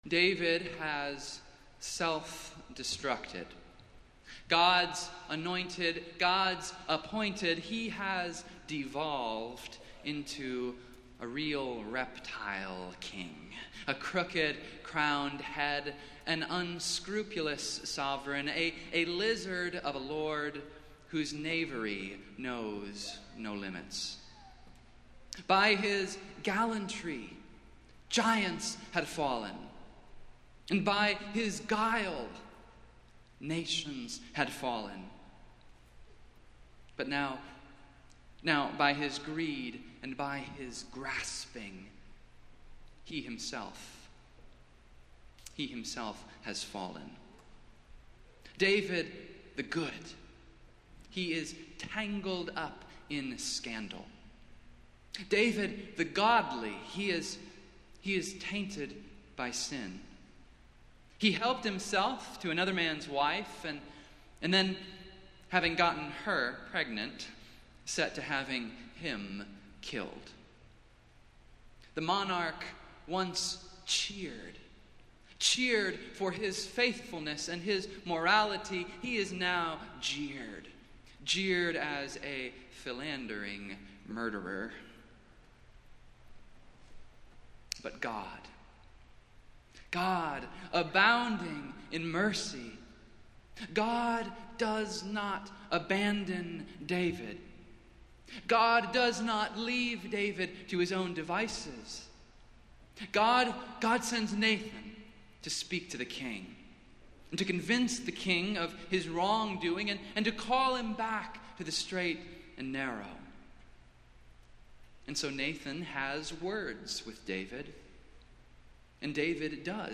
Festival Worship - Tenth Sunday after Pentecost